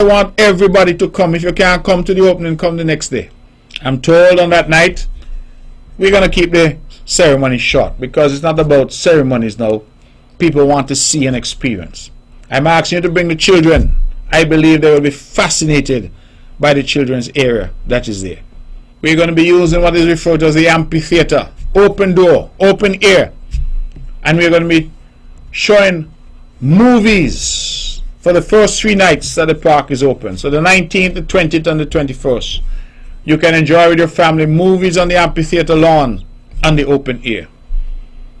Premier Brantley gave these details during the latest edition of the On the Mark Program on what the public can expect at the park on the opening day: